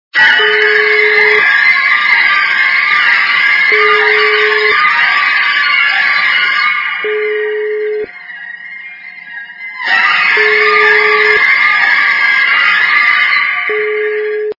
Из фильмов и телепередач